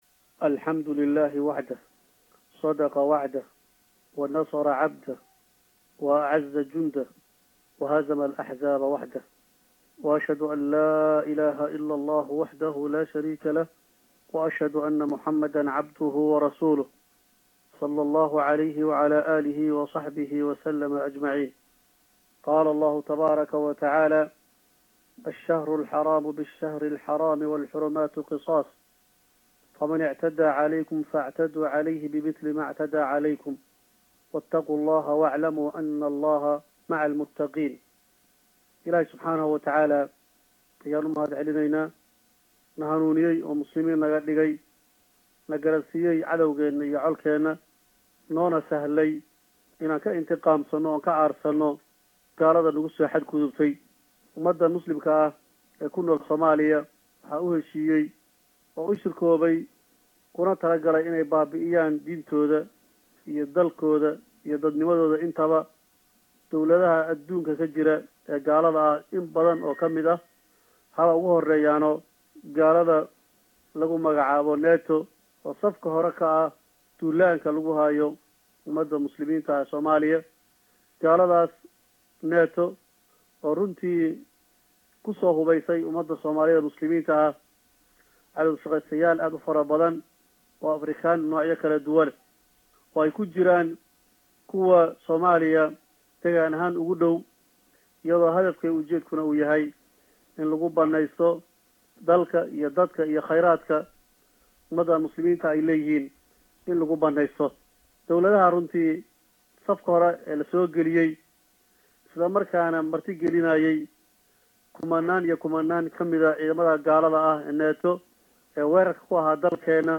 cali dhereAfhayeenka Alshabaab Sheekh Cali Maxamuud Raage Sheekh Cali dheere ayaa shir Jaraa’id oo uu galabta qabtay ku faah faahiyay weerarkii khasaaraha badan dhaliyay ee Jabuuti ka dhacay.